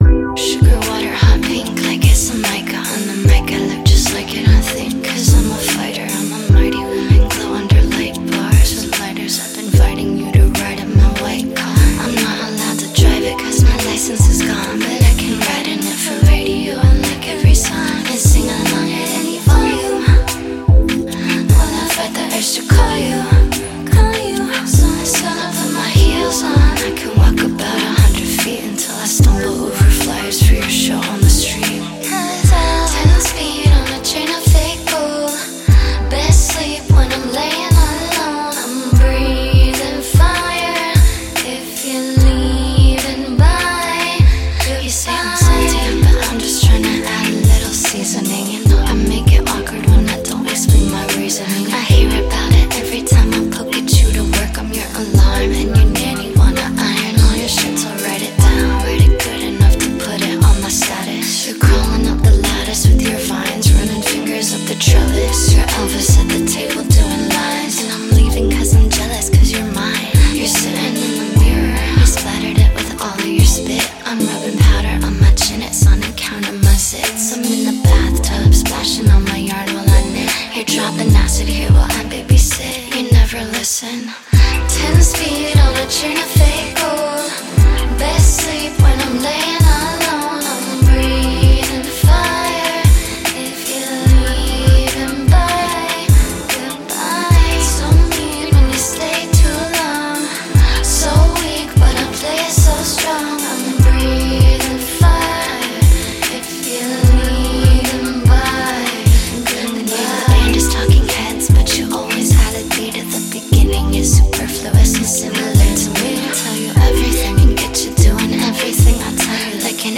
Alternative, Electronic, Trip-Hop